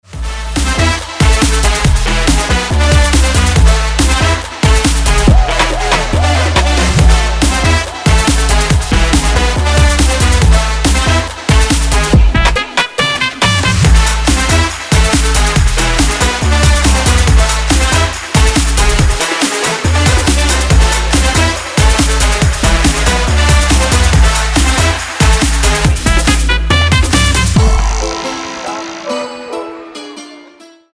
• Качество: 320, Stereo
зажигательные
Electronic
без слов
Hard House
Зажигательный латинский рингтон в стиле hard house